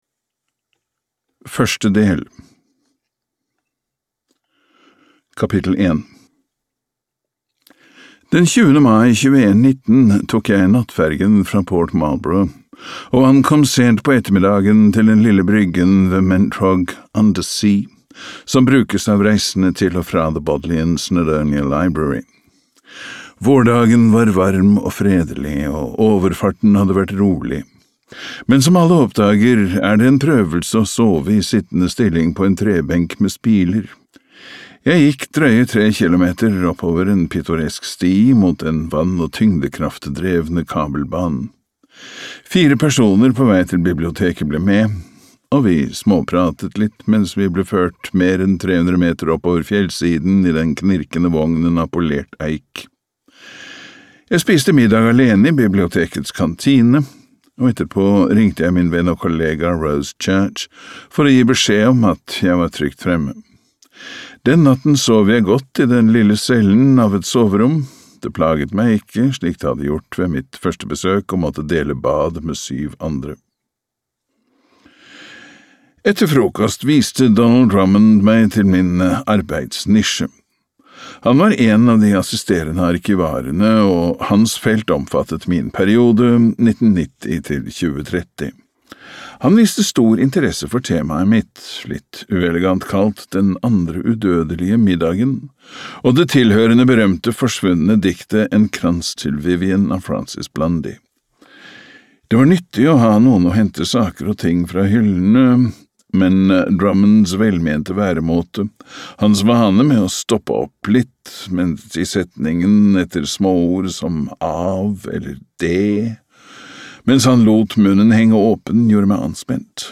Hva vi kan vite (lydbok) av Ian McEwan